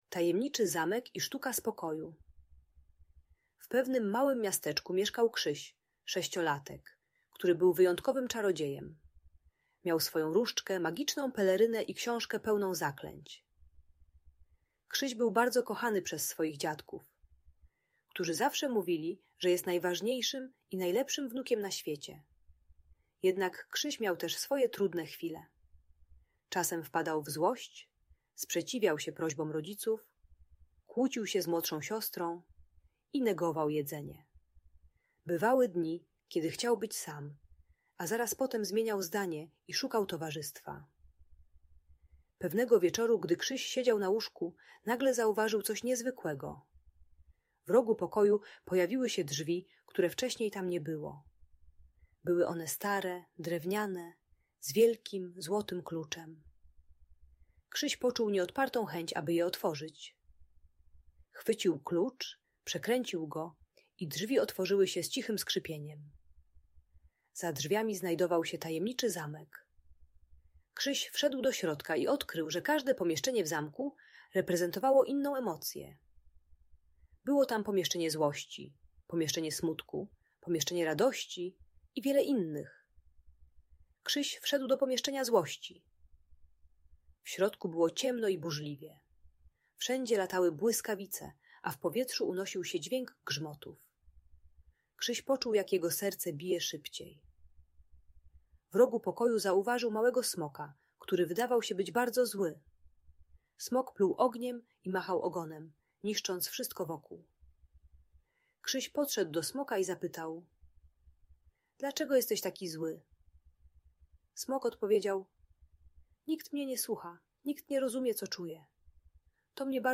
Tajemniczy Zamek i Sztuka Spokoju - Audiobajka